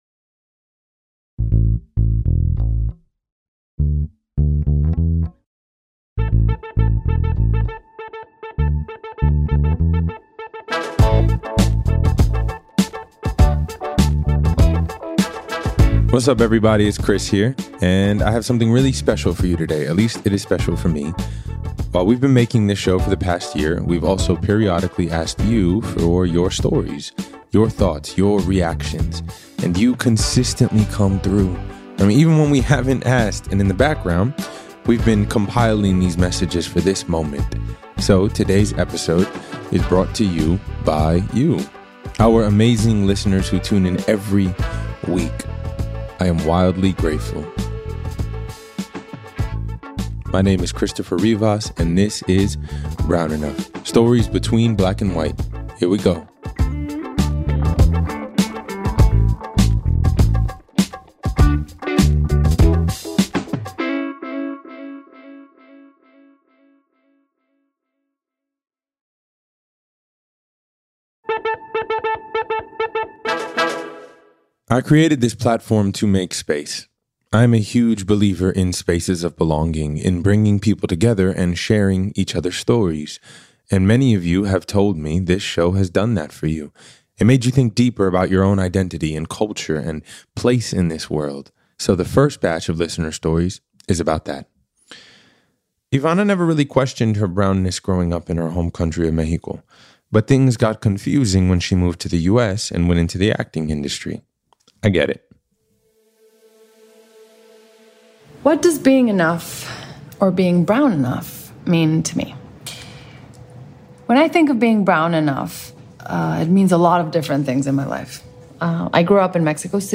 We’ve been asking for your stories, thoughts, and reactions - and today you’ll get to hear all the beautiful messages you’ve been sending over time..